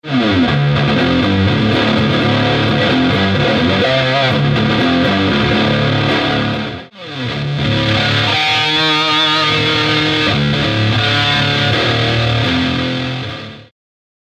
This deep bass distortion pedal offers excellent cost performance.
The bottom knob controls the low frequencies, creating a powerful presence in the sound.